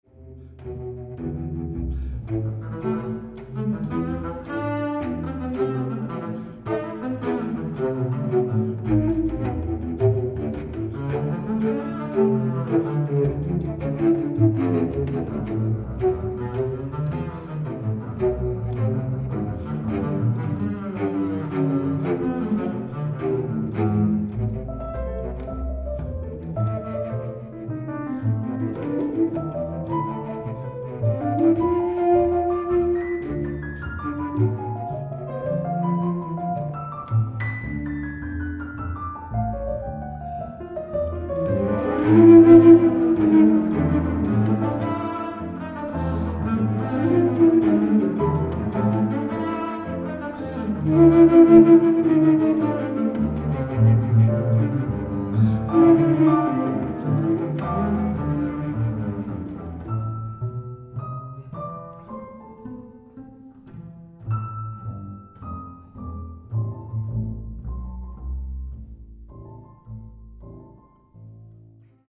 Australian classical music
bass, contrabass and sub-contrabass flutes